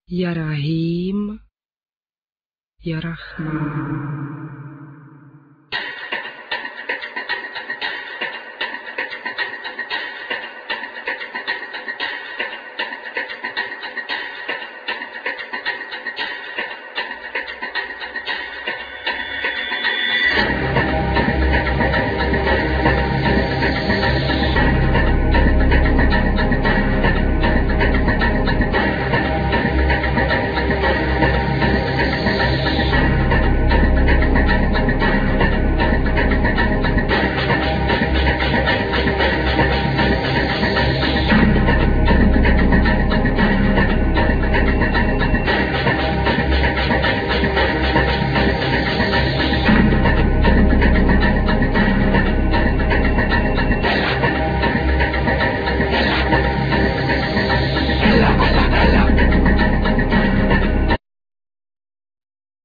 Vocal,Throat vocal,Djembe,Rattles,Indian flute,Sounds
Drums,Percussions,Groove box,Synth,Loop
Bass Hammond,Synth,Sample
Guitar loop